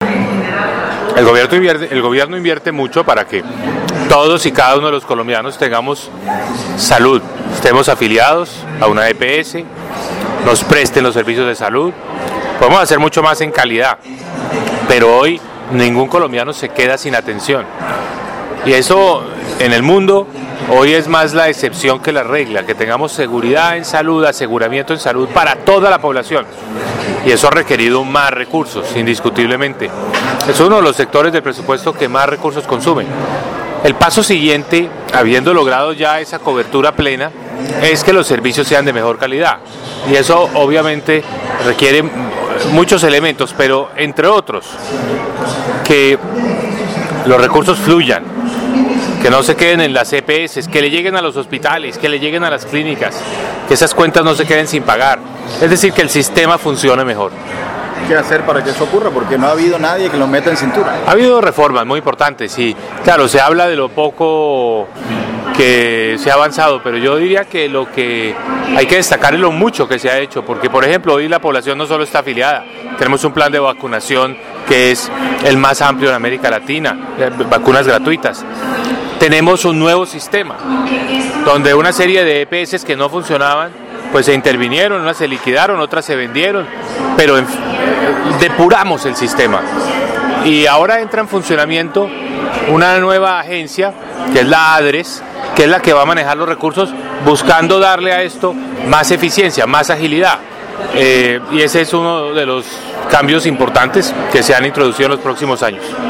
«Se han tomado todas las medidas para brindarle salud de buena calidad a nuestra población, es el sector al que más recursos se le asignan, se ha logrado una cobertura total, se realizan jornadas de vacunación de las mejores del continente, por eso ahora la prioridad es mejorar la calidad de esos servicios», anunció el jefe de la cartera de Hacienda, en diálogo con Atlántico en Noticias.